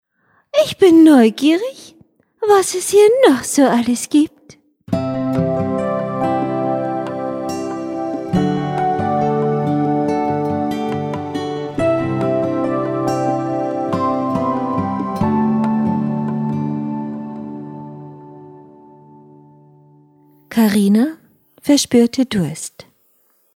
Karina war hier Hörgeschichte